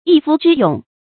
一夫之勇 注音： ㄧ ㄈㄨ ㄓㄧ ㄩㄥˇ 讀音讀法： 意思解釋： 猶言匹夫之勇。